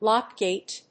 アクセントlóck gàte